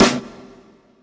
Medicated Snare 6.wav